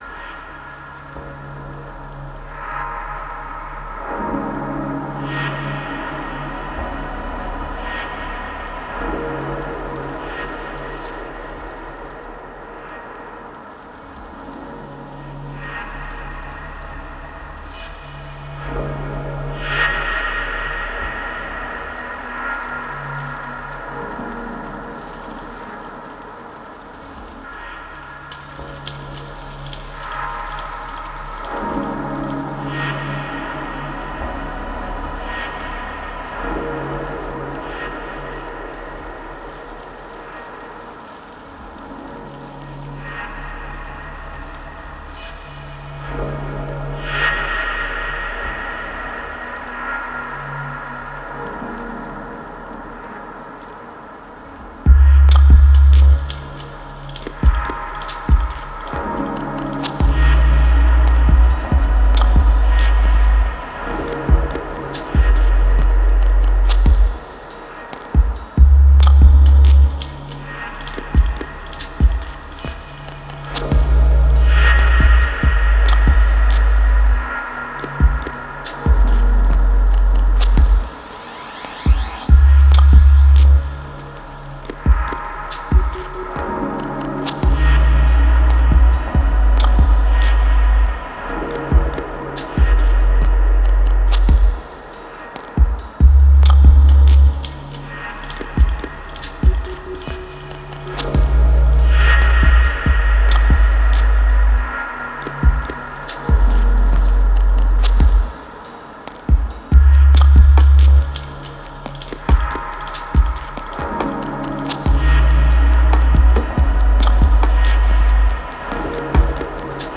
Genre: Downtempo, Dub.